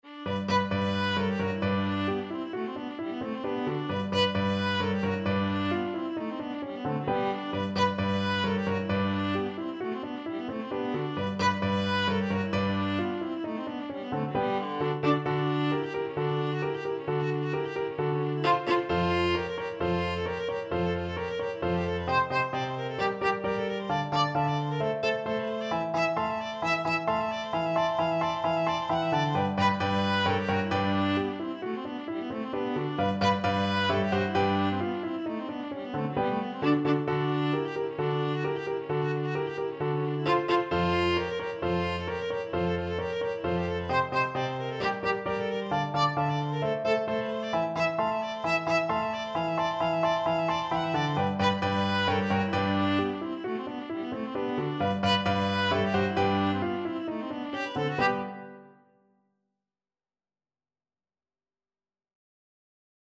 2/4 (View more 2/4 Music)
D4-F#6
Classical (View more Classical Viola Music)